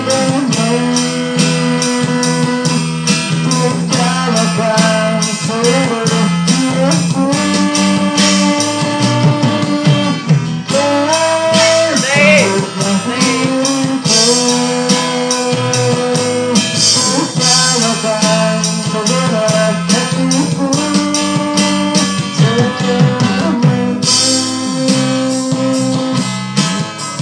AnB Band - latihan untuk single baru